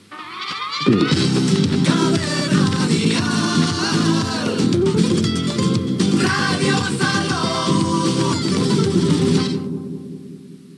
Indicatiu de l'emissora.
Freqüència i indicatiu de l'emissora.